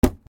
/ H｜バトル・武器・破壊 / H-30 ｜打撃・衝撃・破壊　強_加工済 / 殴る
ダンボールを殴る 衝撃
『ドコ』